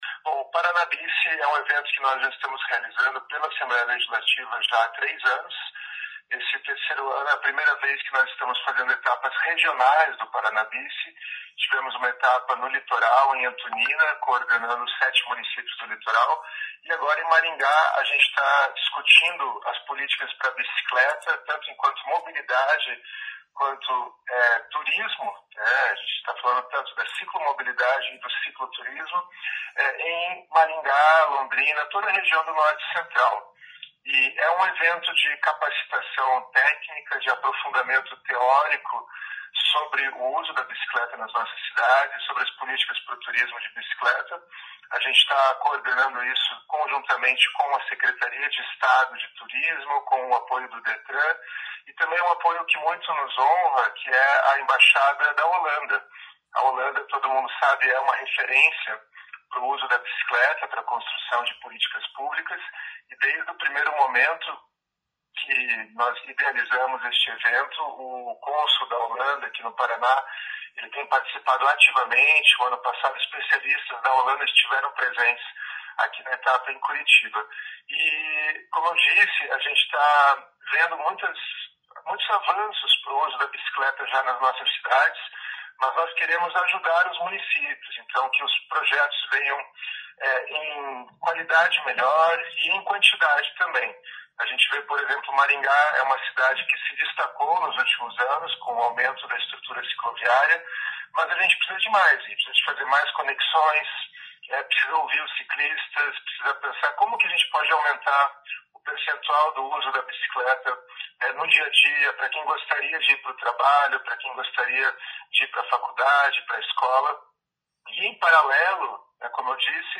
O evento discute a mobilidade urbana por meio do modal bicicleta. O idealizador do Paraná Bici, o deputado estadual Goura, fala sobre o evento.